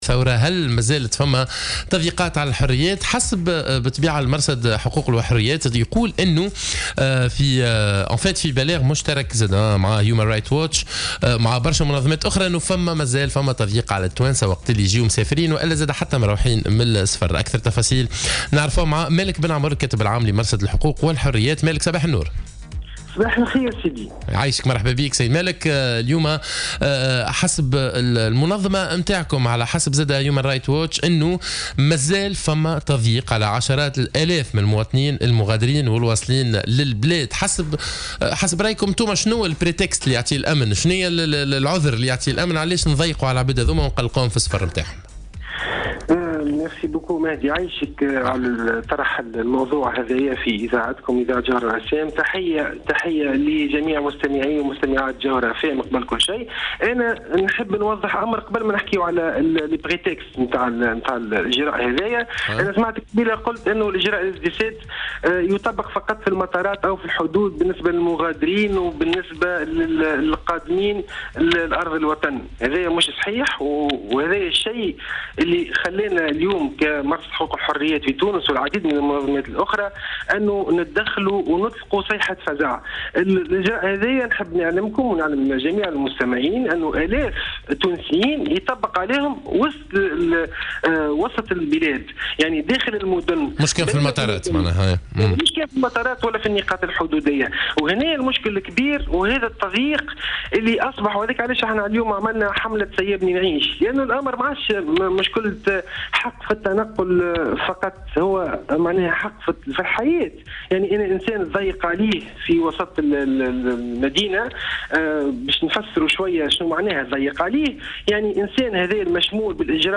مداخلة له على الجوهرة "اف ام"